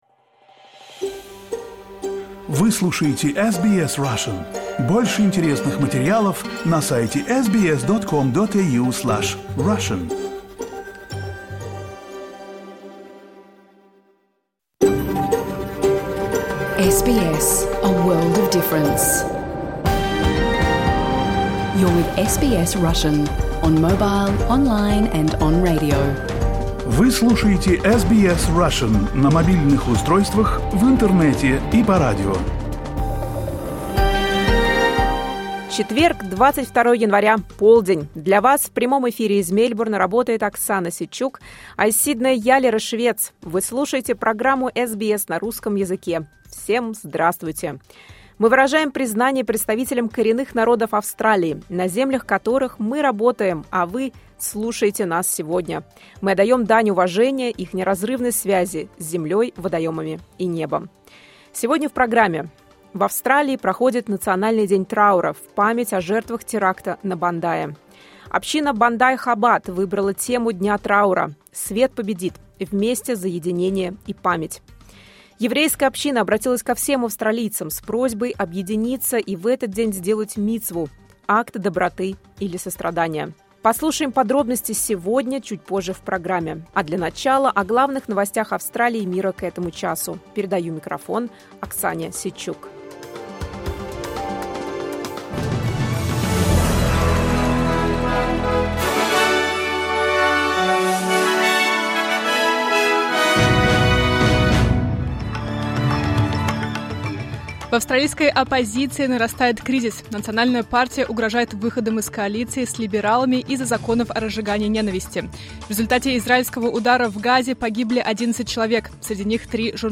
Программу SBS Russian в прямом эфире можно слушать по радио, на нашем сайте и в приложении SBS Audio.